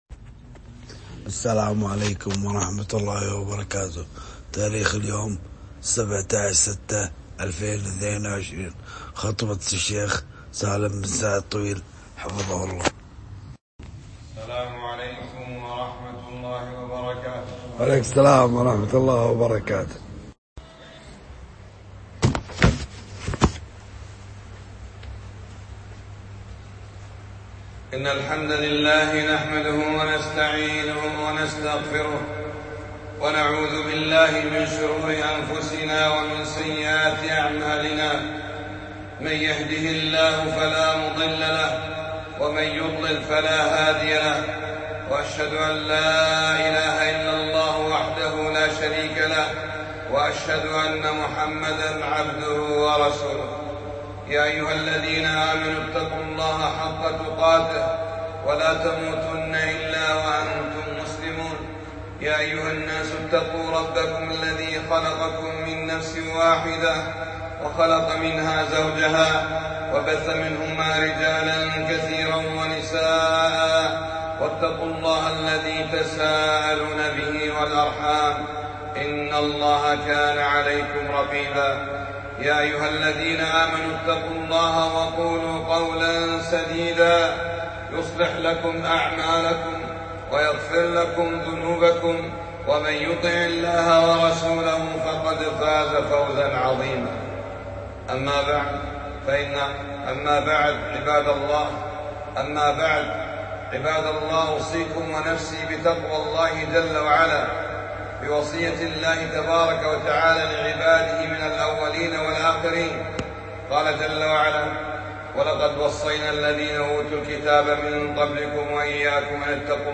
خطبة - نار جهنم أشد حرا فاعتبروا يا أولي الألباب